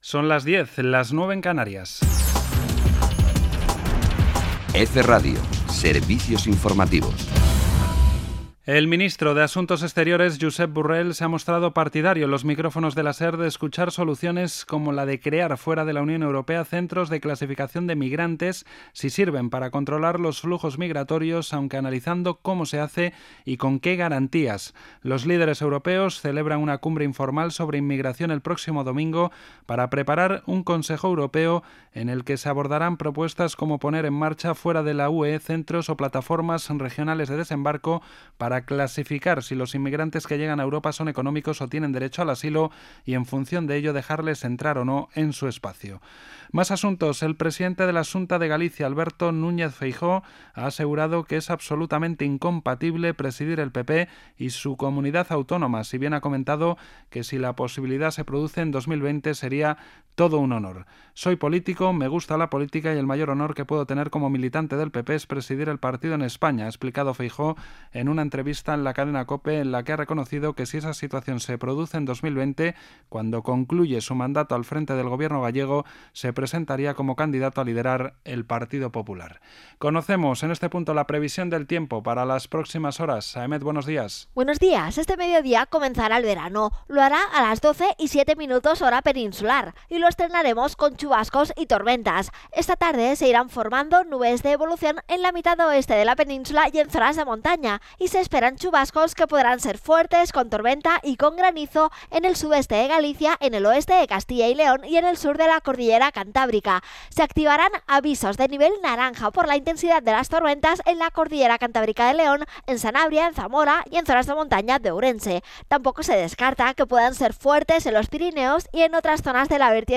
J-BOLETIN 10 00H EFE 2018.